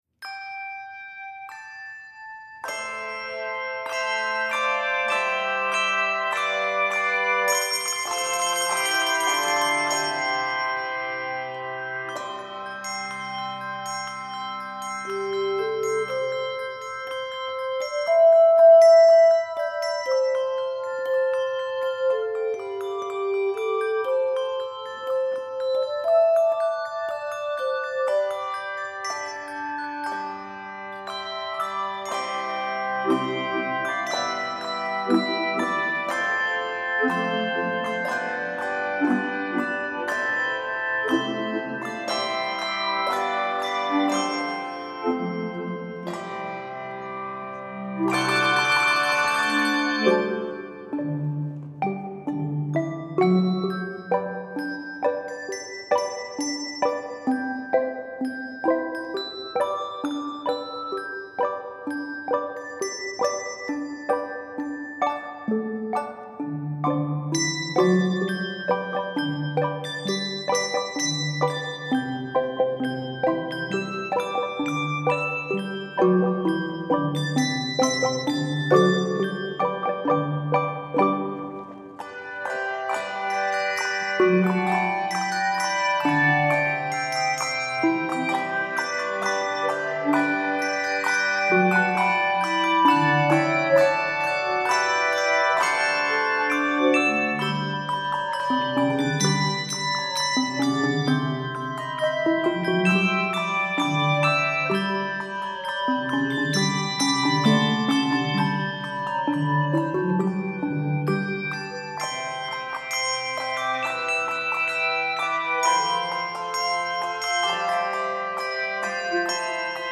Composer: Spiritual
Voicing: Handbells 3-5 Octave